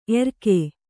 ♪ erke